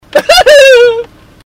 Laugh 38